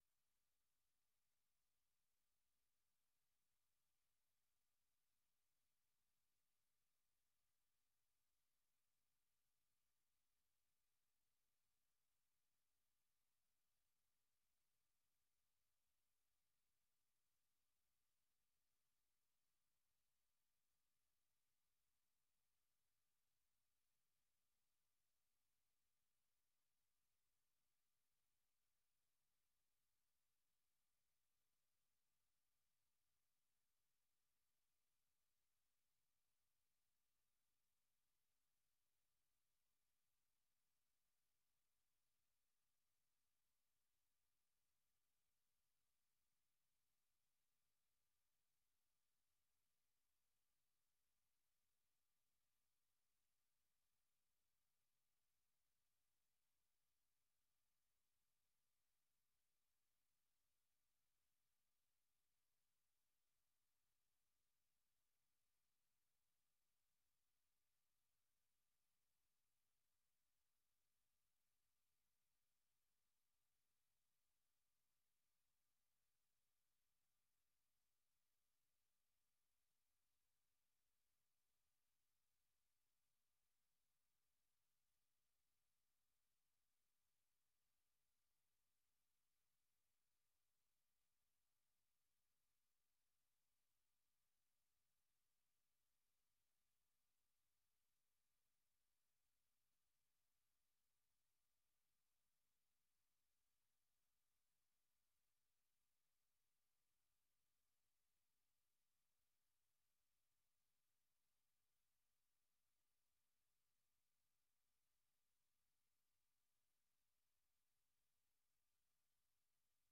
Learning English uses a limited vocabulary and are read at a slower pace than VOA's other English broadcasts.